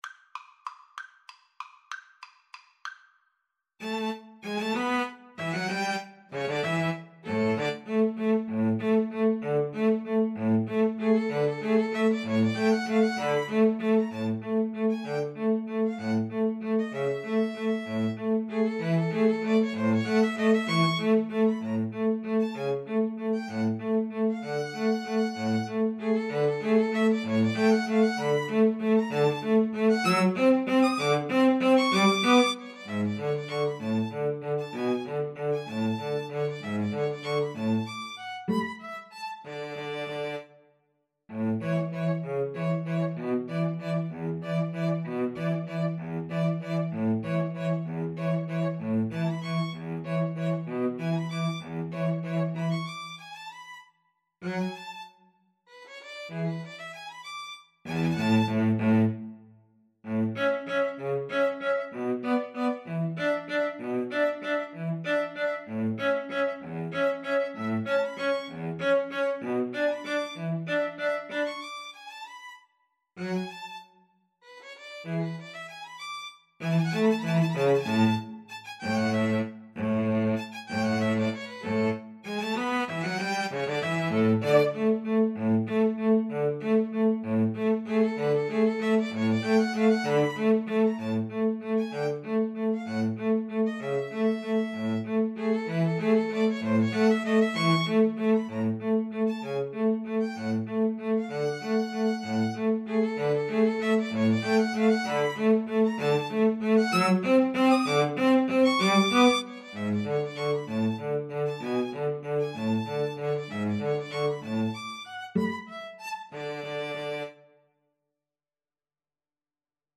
Tempo di Waltz (.=c.64)
Classical (View more Classical String trio Music)